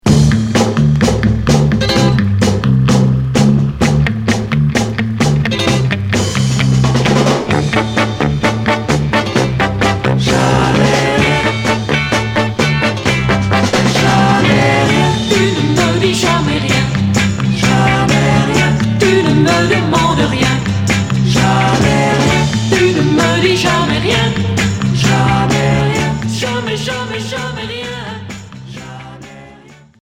Pop 60's